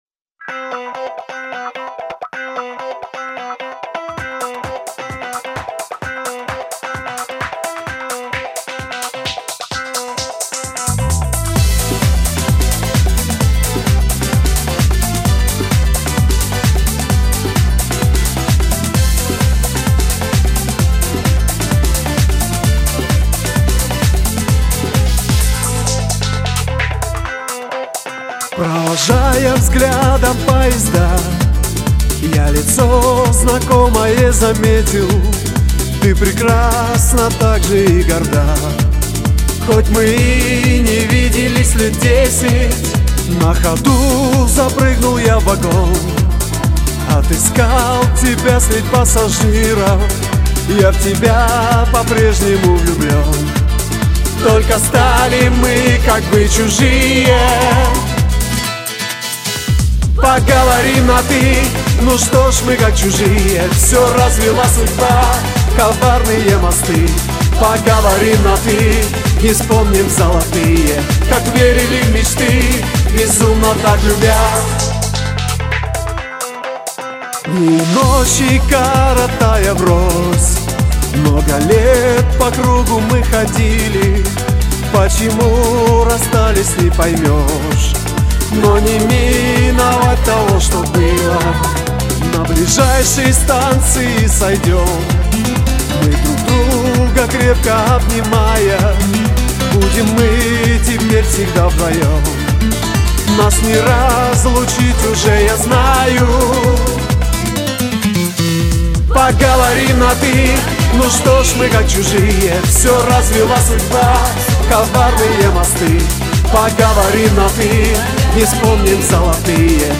Немного цыганщины и чуть-чуть грусти.